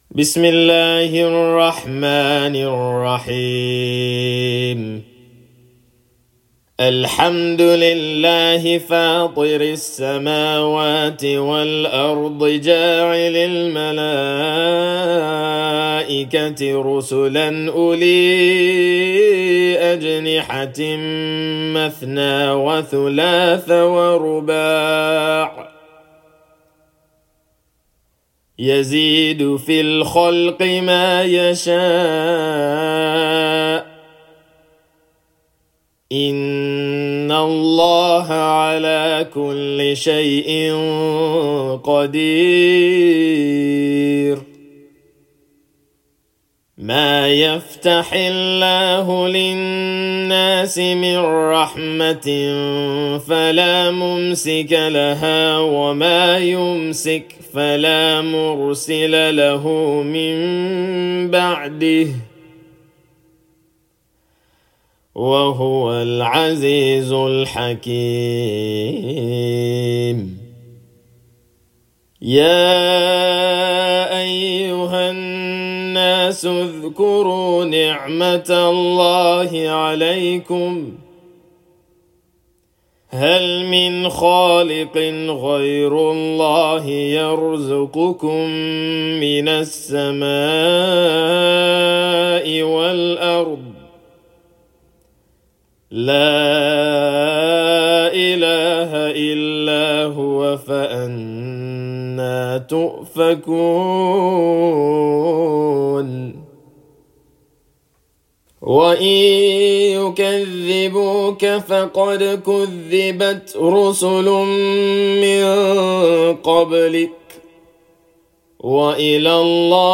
Chapter_35,_Fatir_(Murattal)_-_Recitation_of_the_Holy_Qur'an.mp3